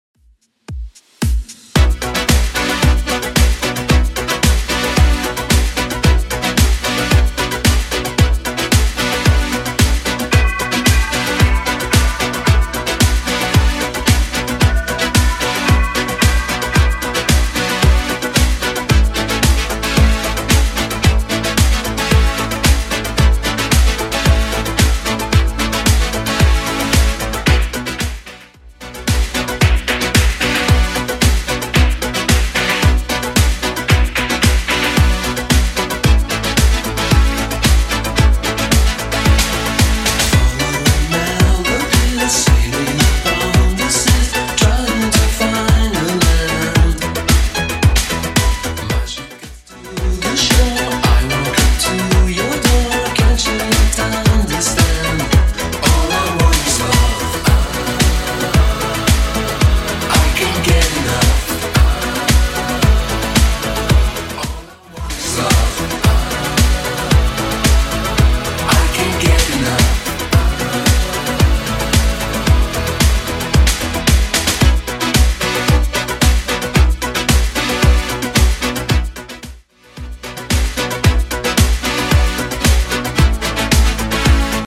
Genre: 80's
BPM: 111